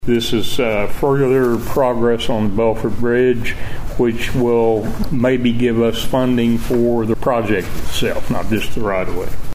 District Three Commissioner Charlie Cartwright